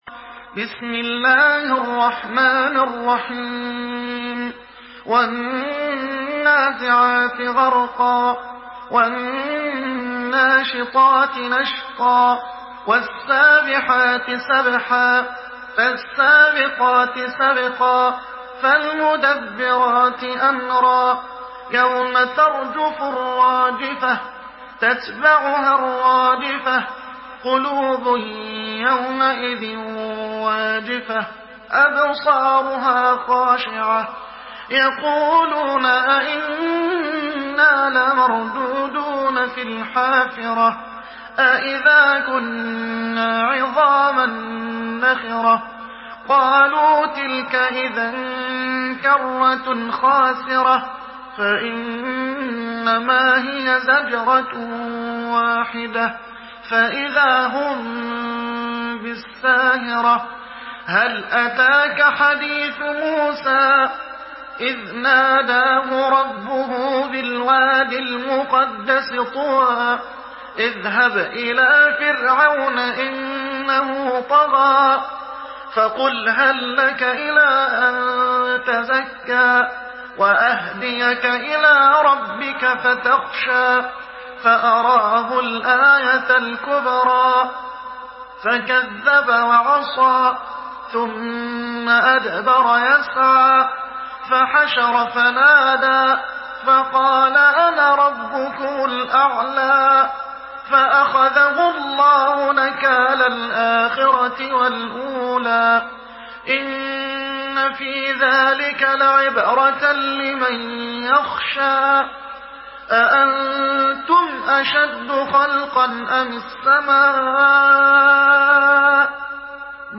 مرتل حفص عن عاصم